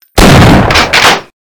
Download Half Life Shotgun sound effect for free.
Half Life Shotgun